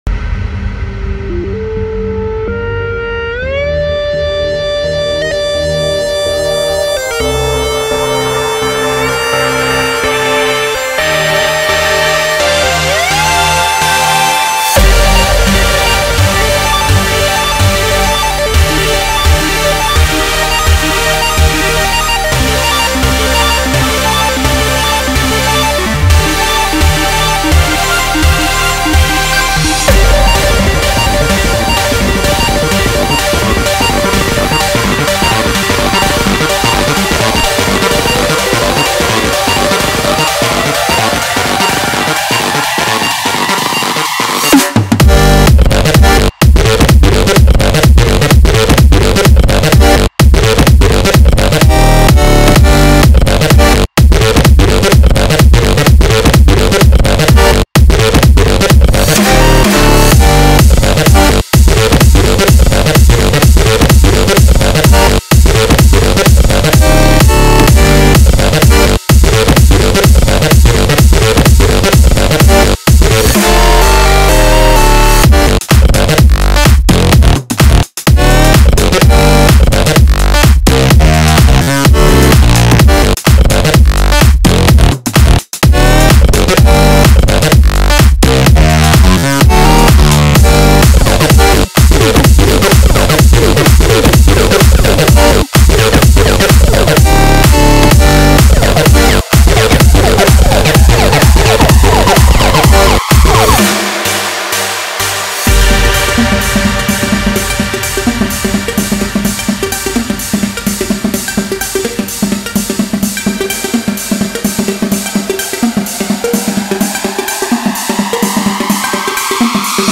Juego de esquiva magnético adictivo con música épica.